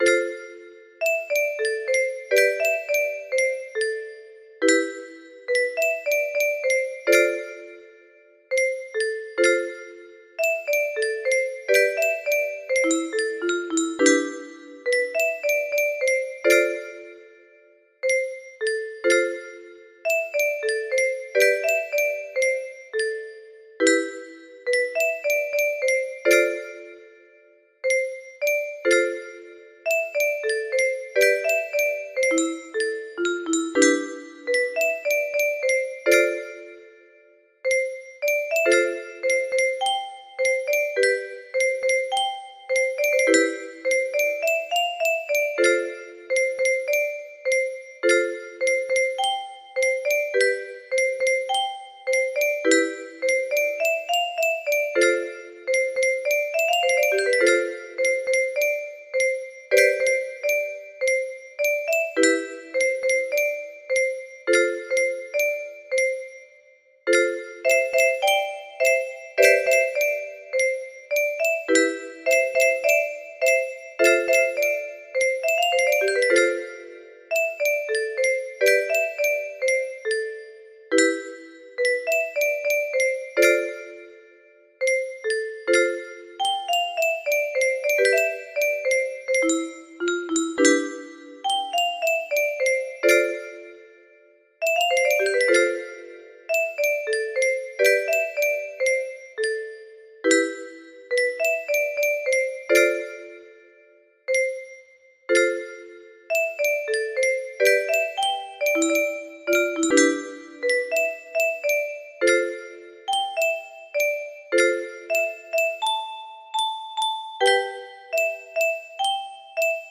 Imported from Midi file.mid